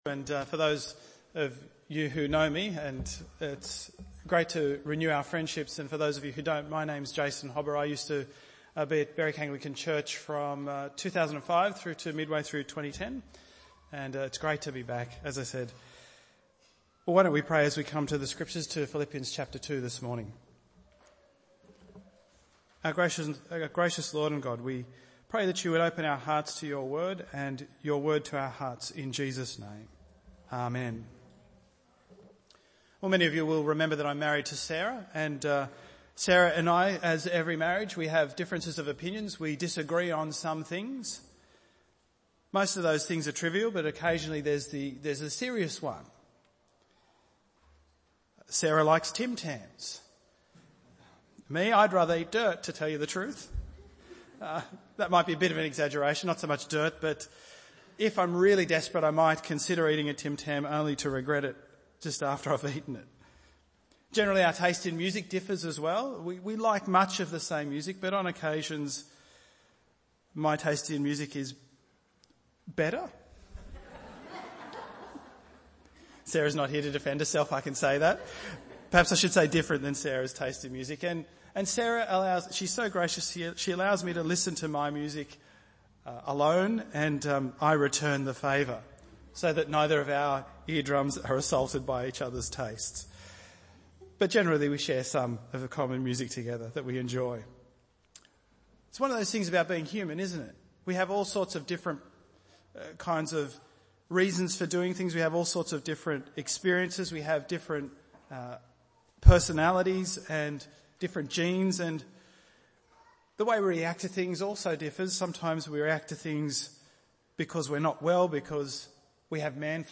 Bible Text: Philippians 2:1-11 | Preacher